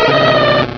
sound / direct_sound_samples / cries / teddiursa.aif
teddiursa.aif